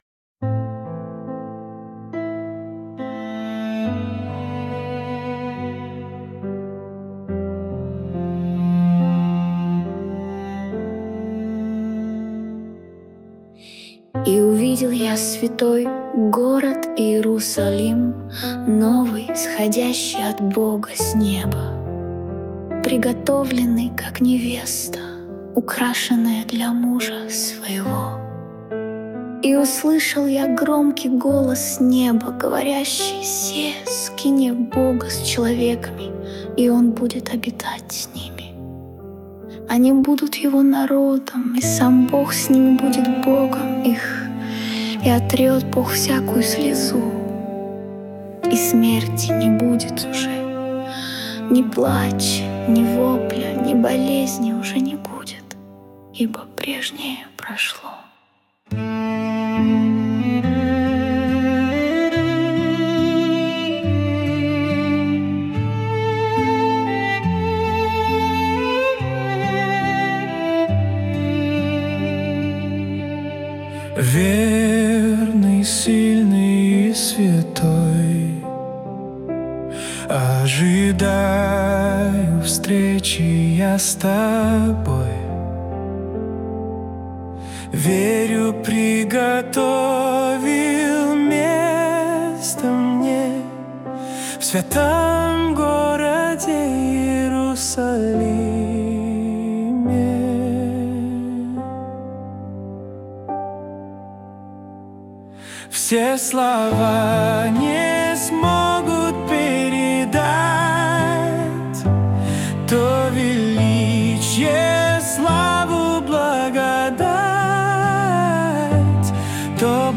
песня ai
269 просмотров 899 прослушиваний 84 скачивания BPM: 70